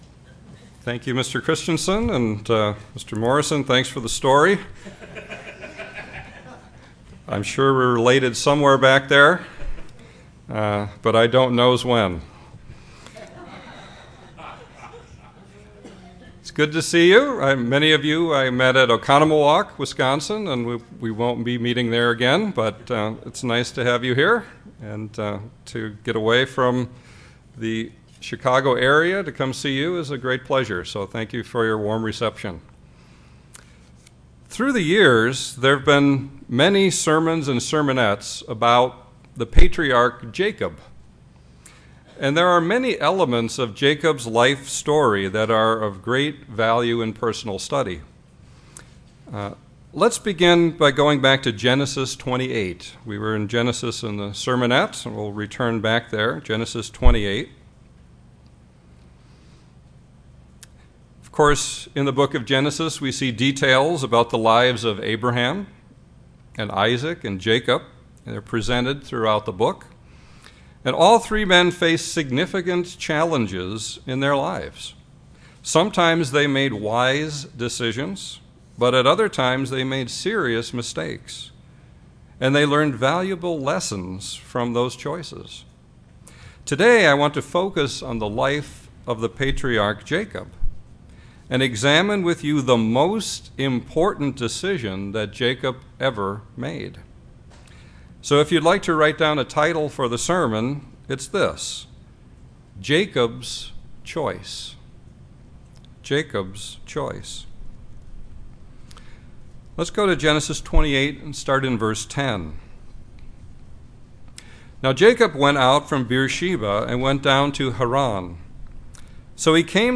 UCG Sermon Jacob Israel Faith submission Studying the bible?